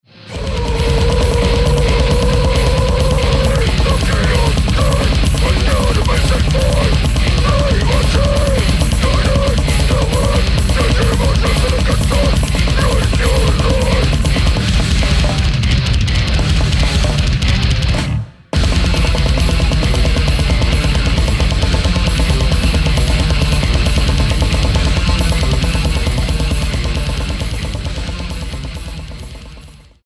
唸る咆哮! 暴虐の重音!!